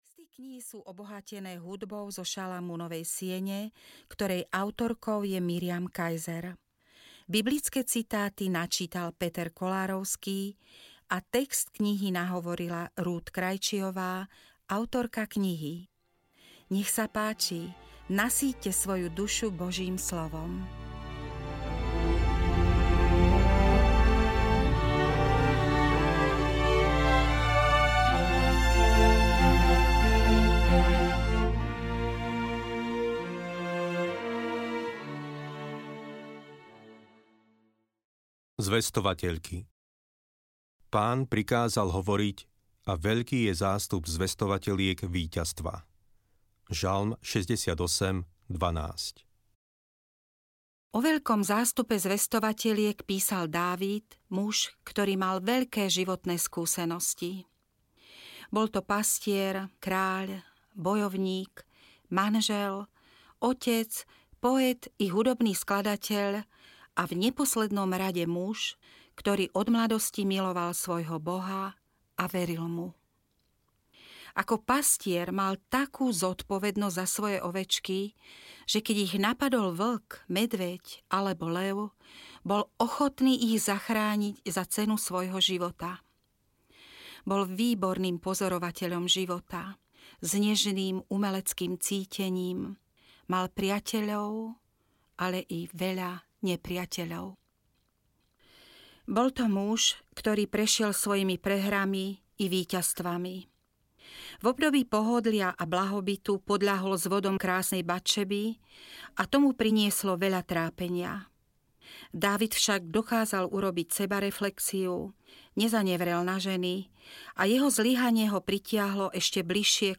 ZVESTOVATEĽKY audiokniha
Ukázka z knihy